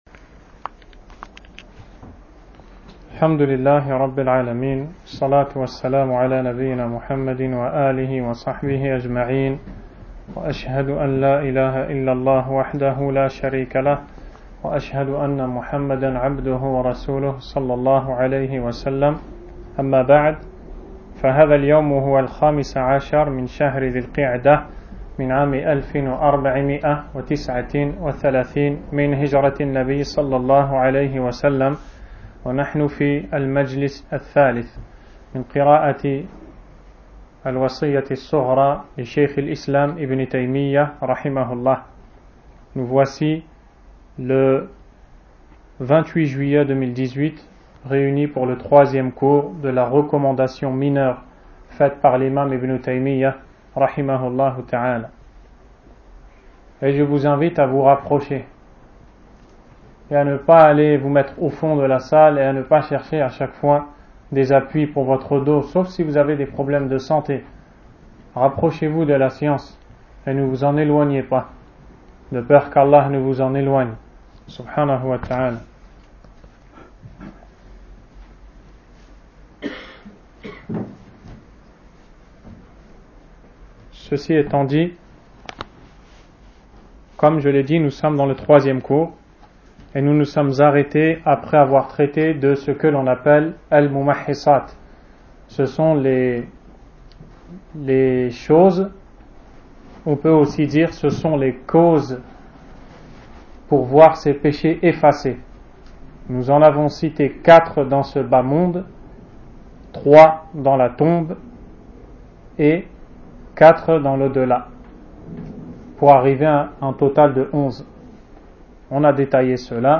Cours 03 : Le bon comportement avec Les gens, L'importance du rappel d'ALLAH exalté soit-il